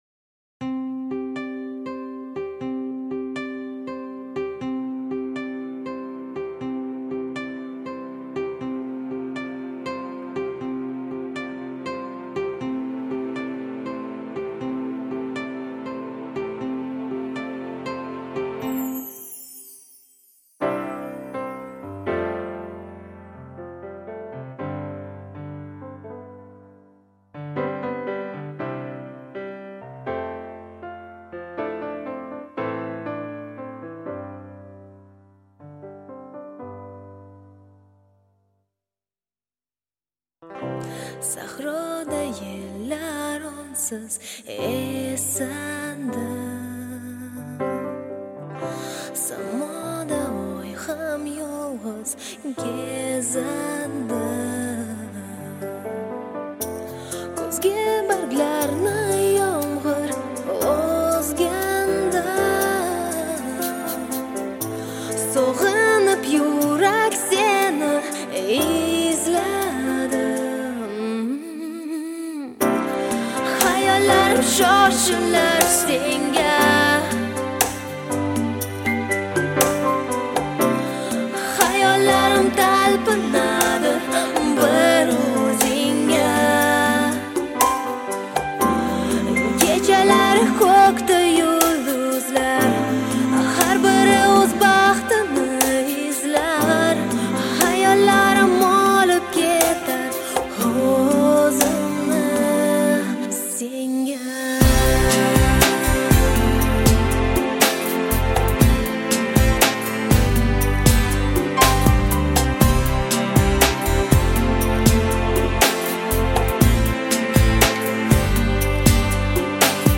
яркая и мелодичная композиция
выполненная в жанре поп.